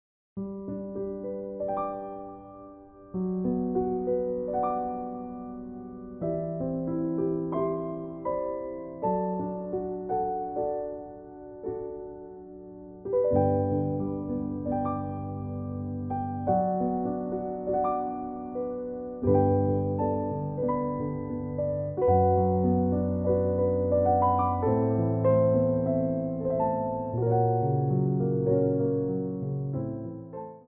Ports de Bras / Revérance
4/4 (8x8)